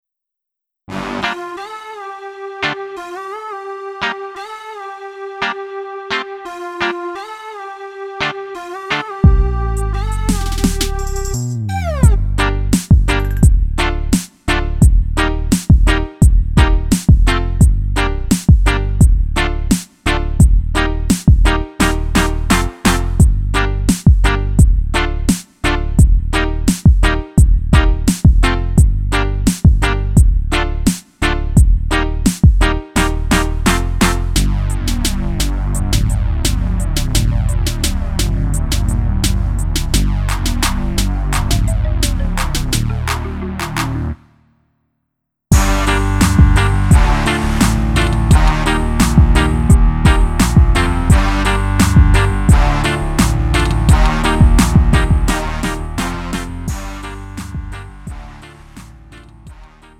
음정 -1키 3:11
장르 가요 구분